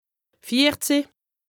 2APRESTA_OLCA_LEXIQUE_INDISPENSABLE_HAUT_RHIN_95_0.mp3